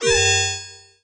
alarm_siren_loop_11.wav